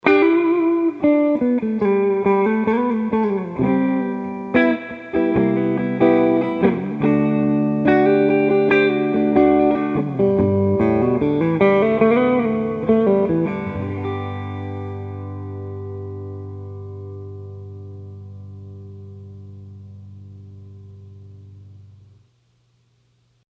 Clean – Neck Pickup
Clean clips were played in the Clean channel of my Fender Hot Rod Deluxe, while the dirty clips were played in the Drive channel.
clean-rhythm.mp3